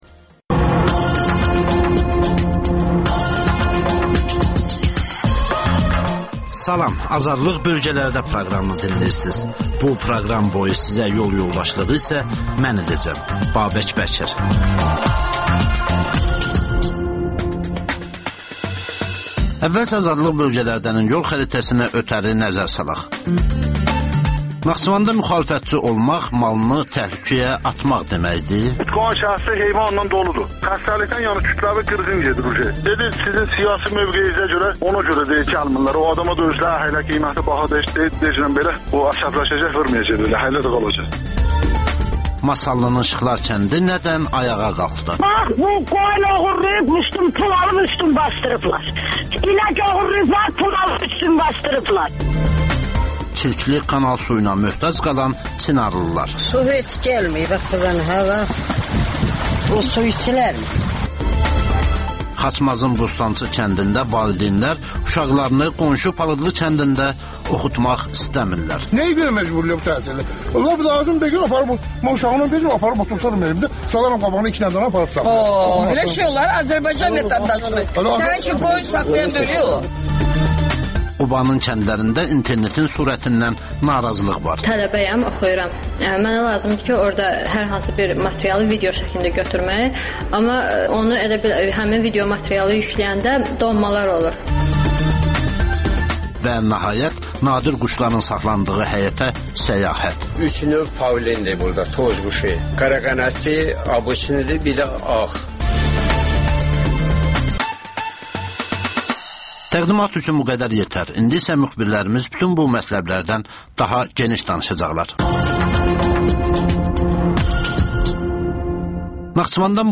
Rayonlardan xüsusi reportajlar